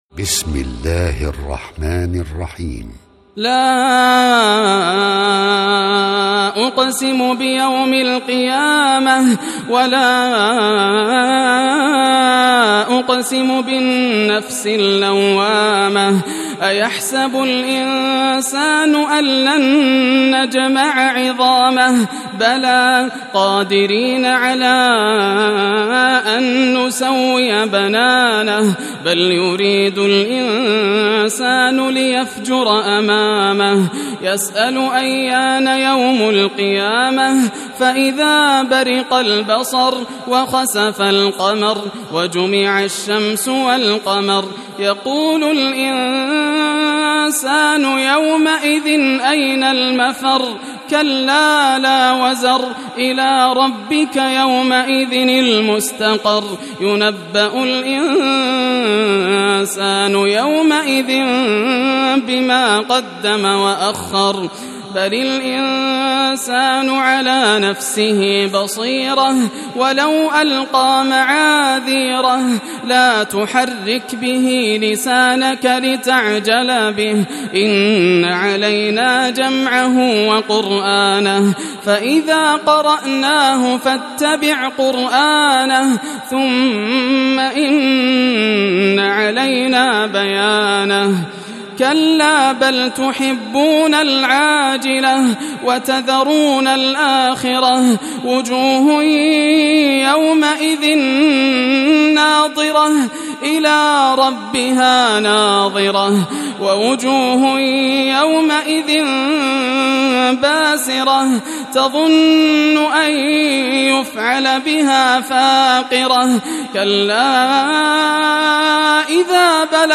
سورة القيامة > المصحف المرتل للشيخ ياسر الدوسري > المصحف - تلاوات الحرمين